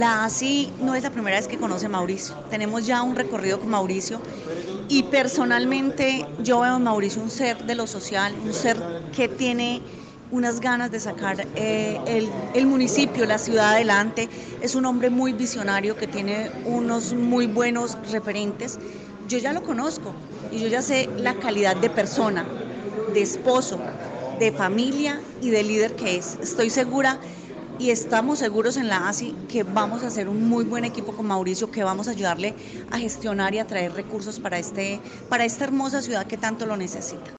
En rueda de prensa donde se hizo la presentación de los candidatos a los diferentes cargos de elección del departamento de Risaralda
Berenice Bedoya Senadora de la ASI